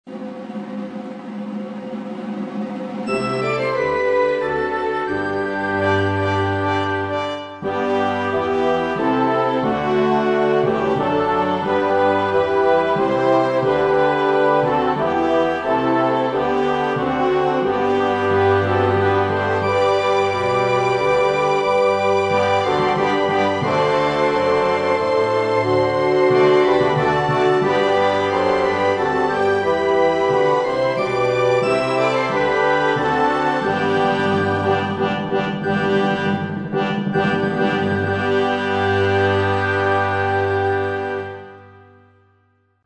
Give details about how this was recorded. Play a demo using sampled sound fonts: